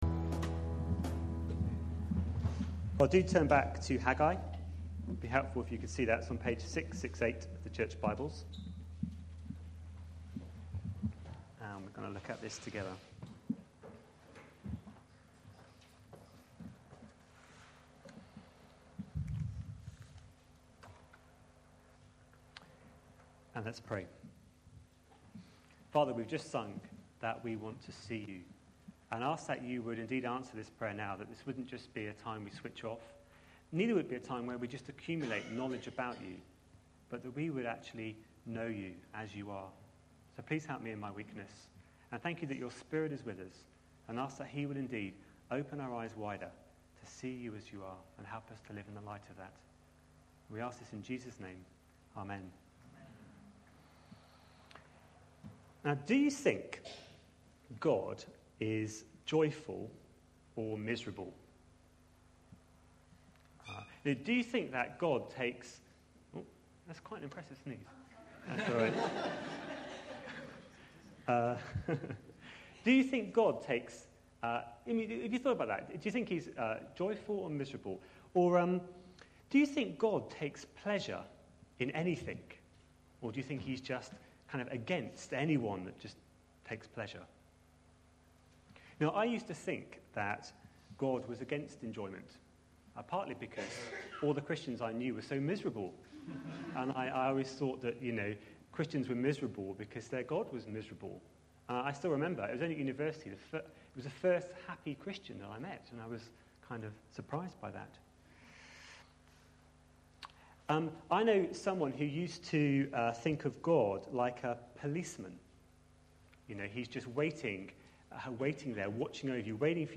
A sermon preached on 8th May, 2011.